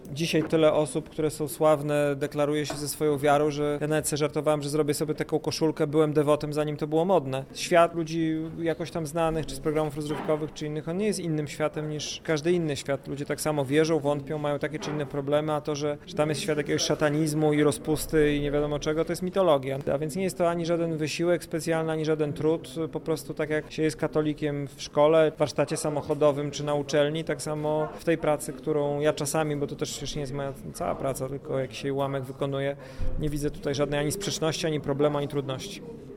W czasie spotkania, które odbyło się w Piekarni Cichej Kobiety, gość opowiedział o podróżach, działalności na rzecz potrzebujących, a także swojej wierze: